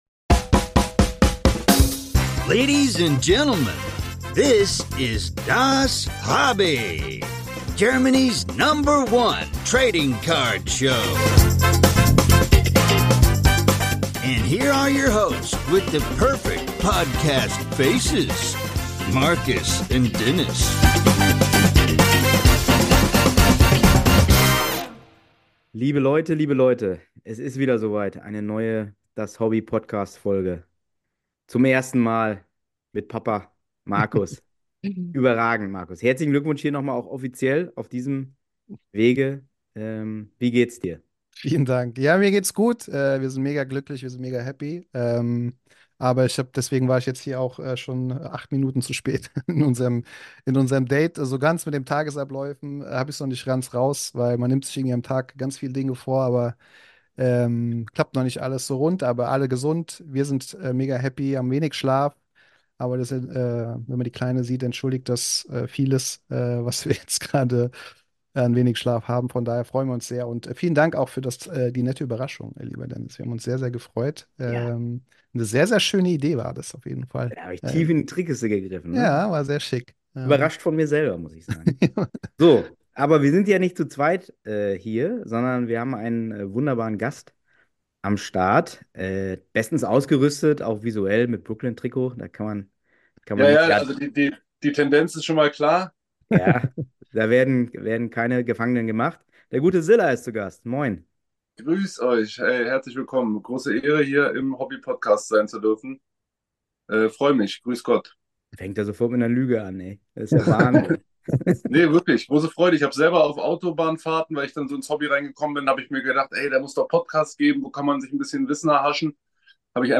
Ein sehr ehrliches & echtes Gespräch ist dabei rausgekommen - soviel können wir euch schon mal versprechen.